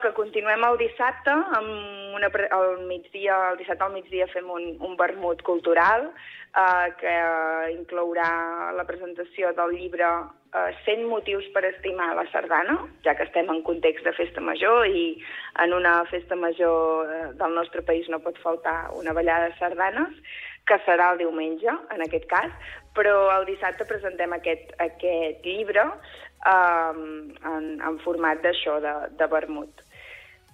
En una entrevista concedida al programa Supermatí, l’alcaldessa de Jafre, Èlia Bantí, ha detallat el programa d’actes de la Festa Major, que tindrà lloc aquest cap de setmana en honor a Sant Martí.